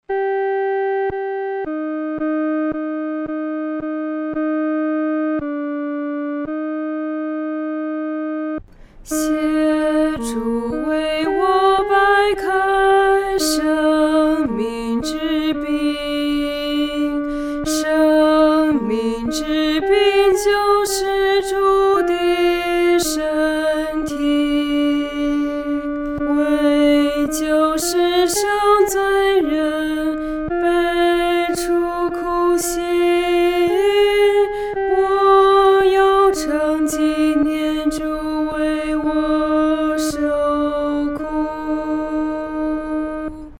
独唱（第二声）
求主掰开生命之饼-独唱（第二声）.mp3